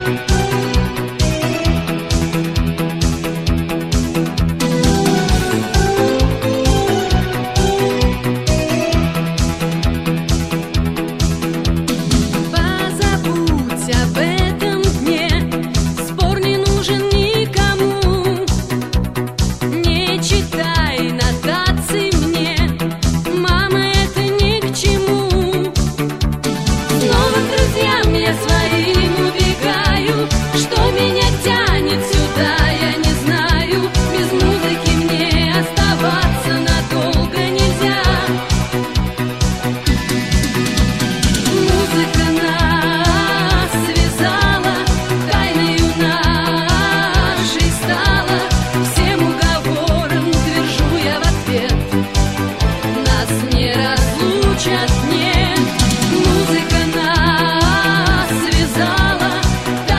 Pop
диско-группы